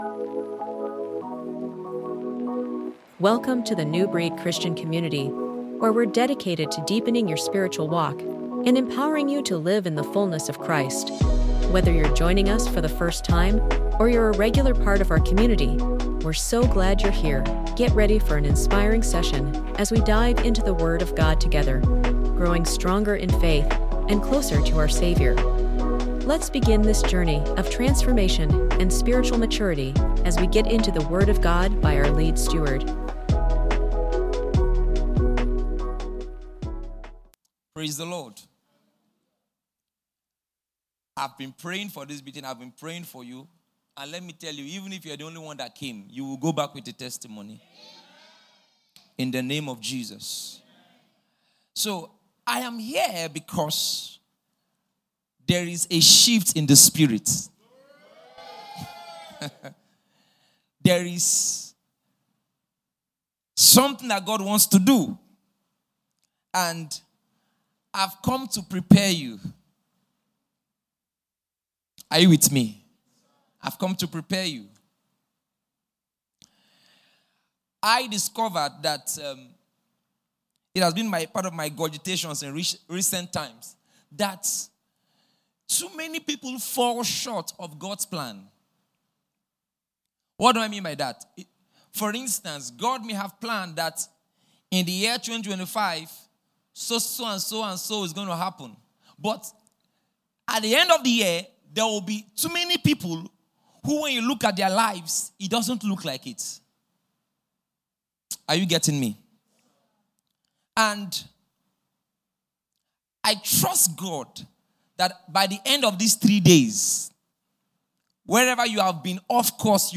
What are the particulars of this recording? We’re excited to bring you a powerful teaching from our special program held in the city of Port Harcourt, titled: 📖 “For I Long To See You”